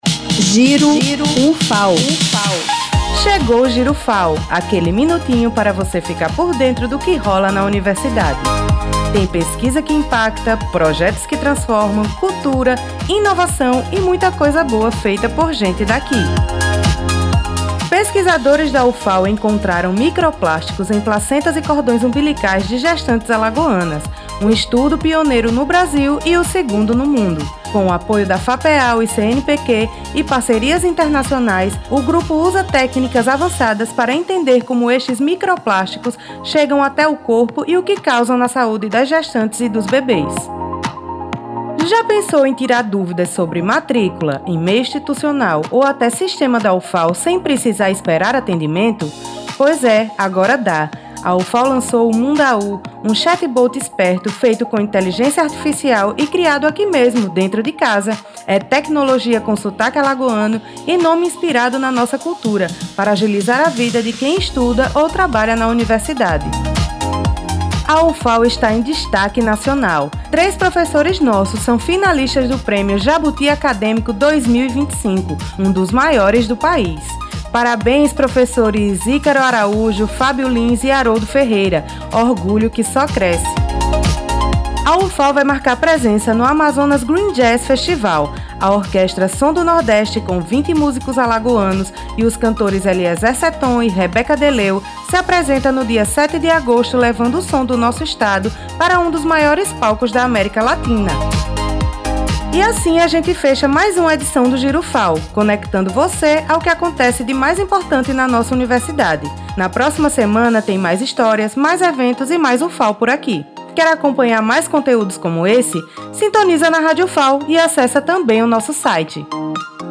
Roteiro e apresentação